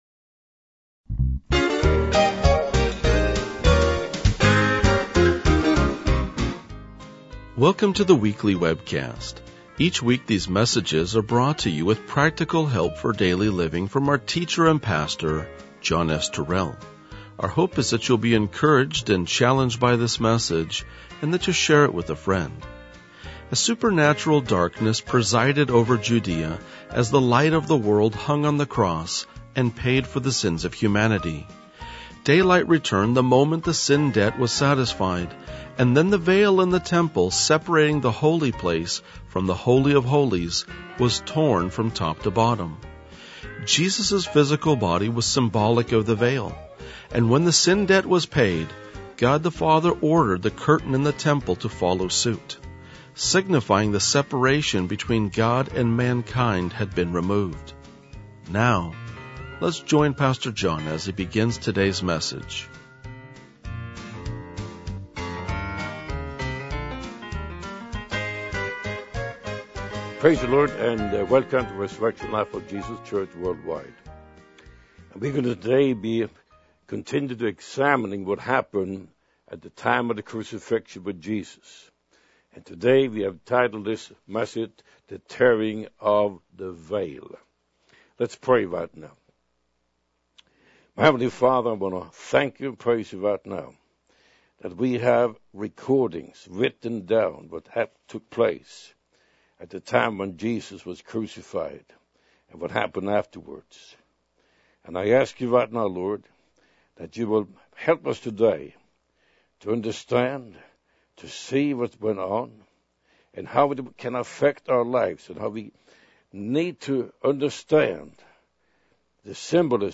RLJ-1987-Sermon.mp3